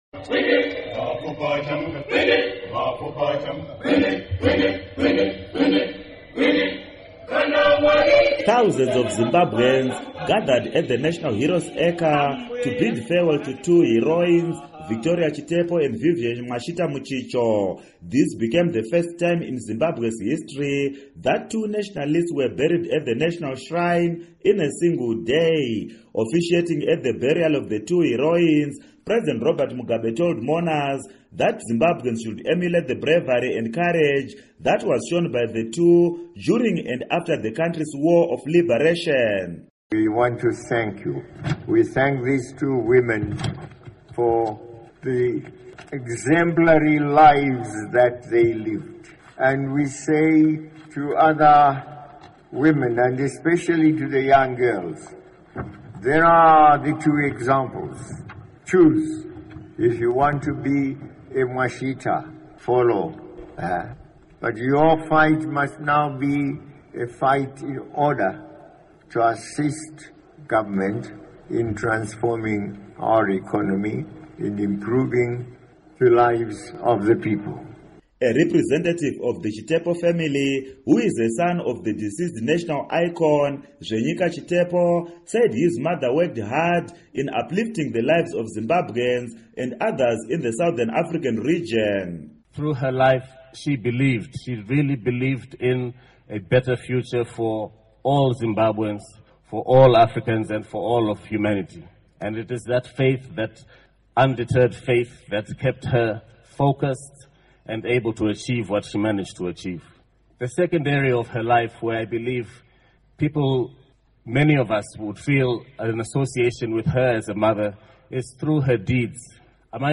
Report on Heroine Burials